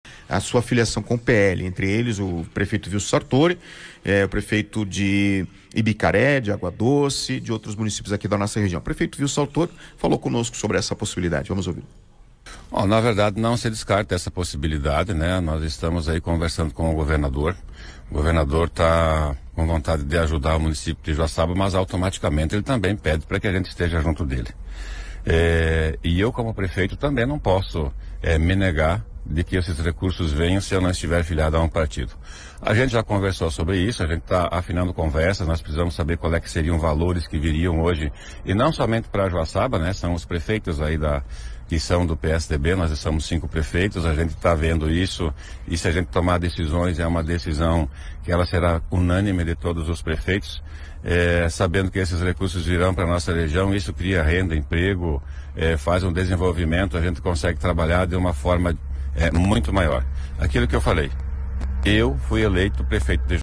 Ele deu uma entrevista para a Rádio Catarinense, da sua cidade, e disse que ele e mais quatro prefeitos podem ir para o PL em troca de verbas do Governo do Estado.
Ouça o áudio do prefeito Vilson Sartori (Joaçaba):